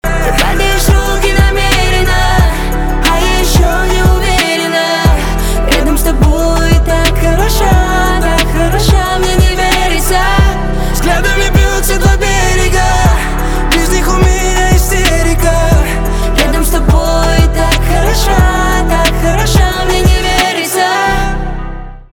поп
битовые